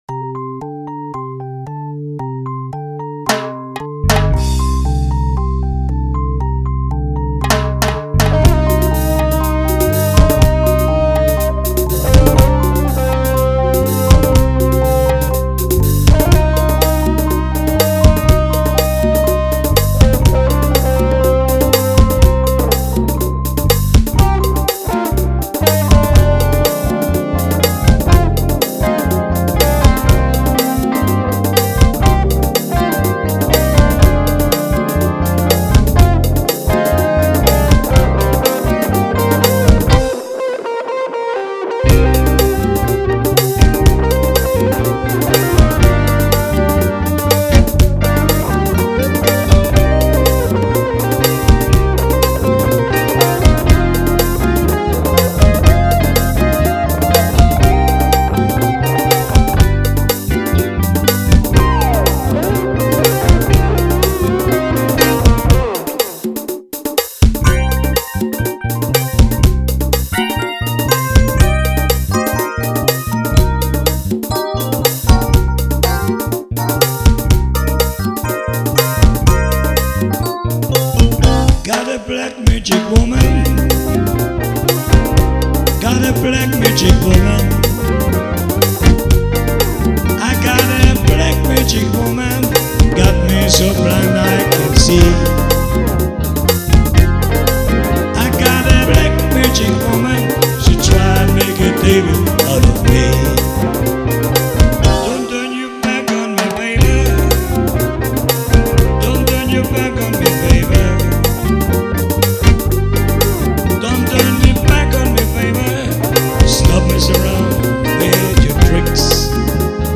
rocking